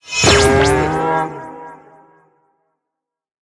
Minecraft Version Minecraft Version 1.21.4 Latest Release | Latest Snapshot 1.21.4 / assets / minecraft / sounds / mob / mooshroom / convert2.ogg Compare With Compare With Latest Release | Latest Snapshot